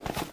STEPS Dirt, Run 27.wav